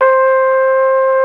Index of /90_sSampleCDs/Roland LCDP12 Solo Brass/BRS_Flugelhorn/BRS_Flugelhorn 1